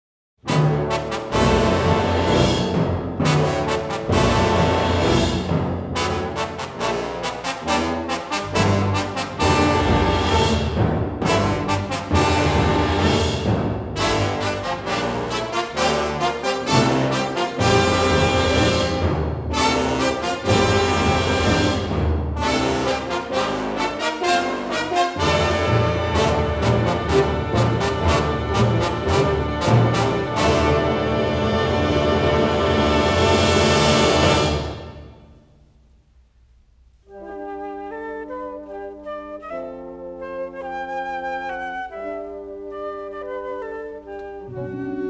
in stereo sound